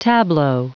Prononciation du mot tableau en anglais (fichier audio)
Prononciation du mot : tableau